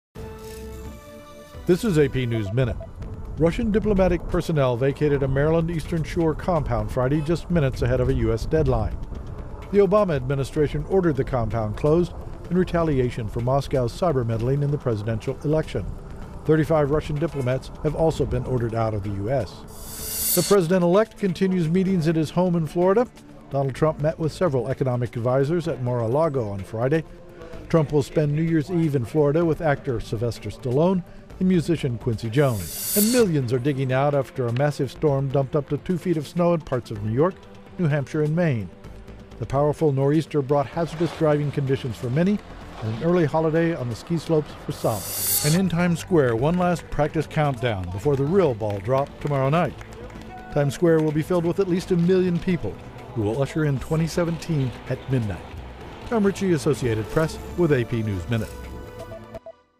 News
美语听力练习素材:时报广场举行跨年倒计时